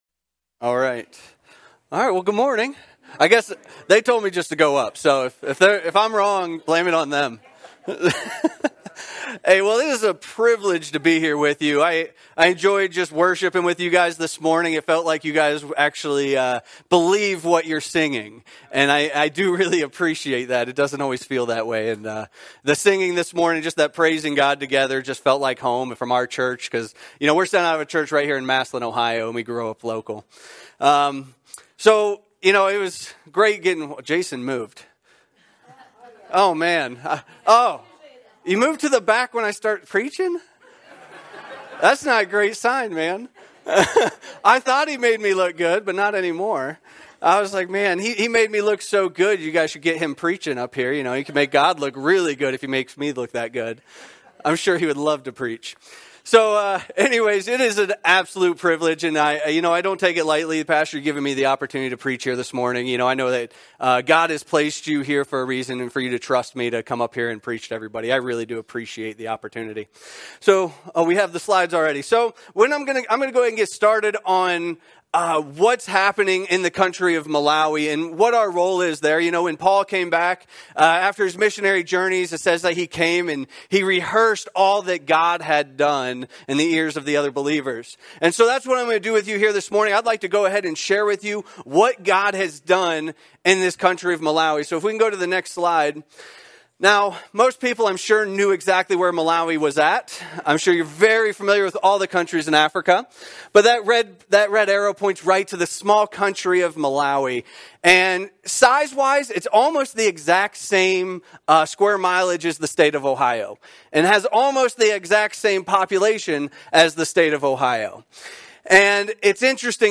ACC Sermons
From Series: "Sunday Morning Service"